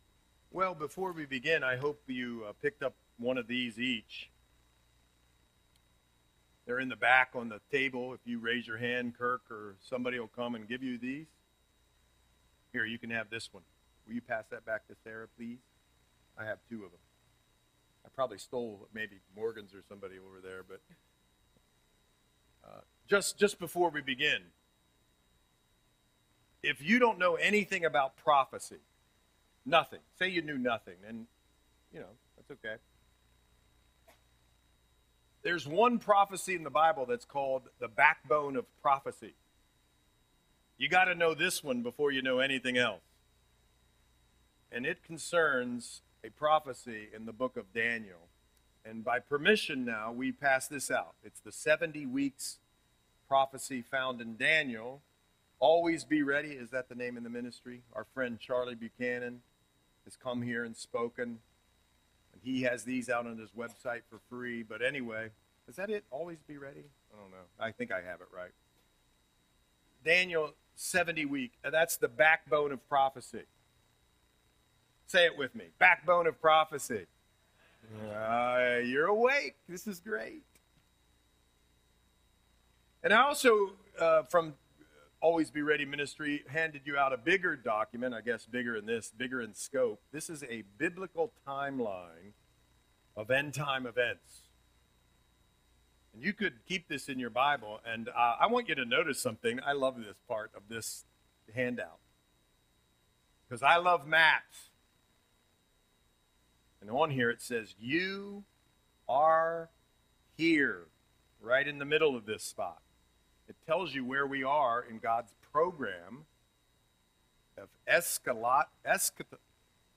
Audio Sermon - March 2, 2025